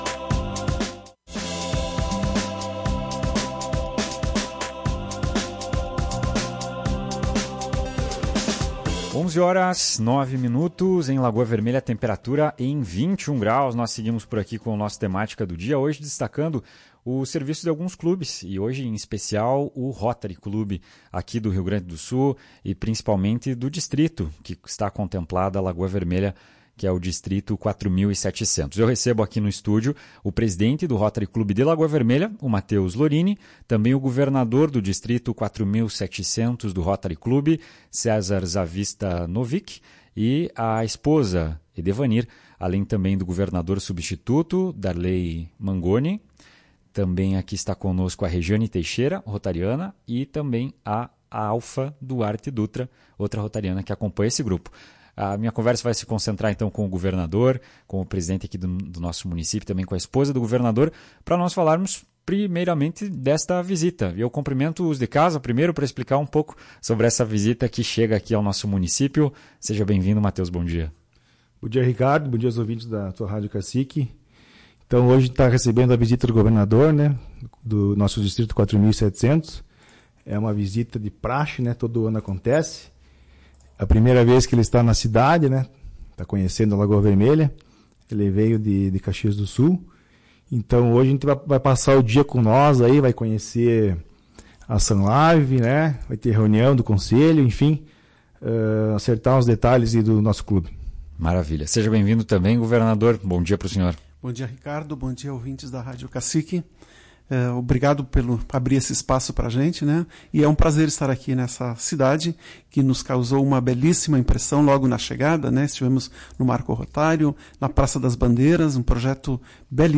Durante entrevista à Tua Rádio Cacique, ele ressaltou a importância das ações realizadas em parceria com os governos e a Defesa Civil para ajudar as comunidades afetadas.